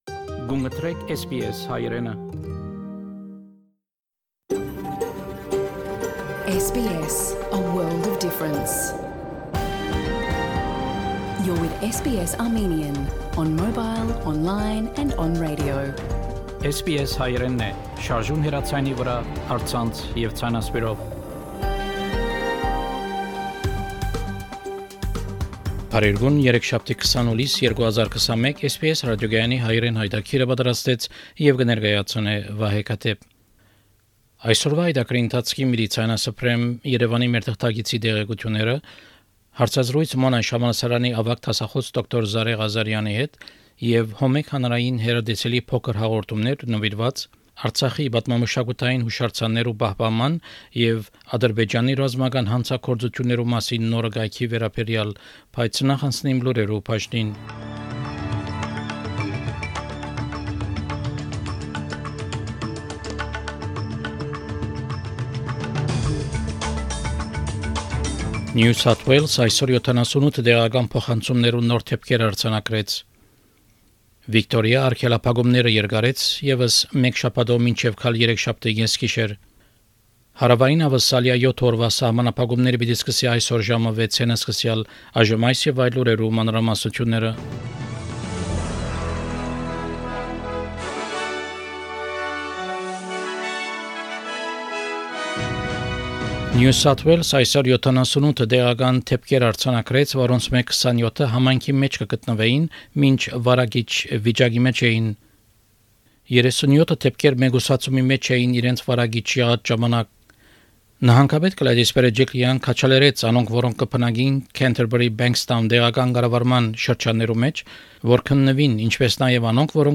SBS Armenian news bulletin – 20 July 2021